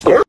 Dog Gorp Short Download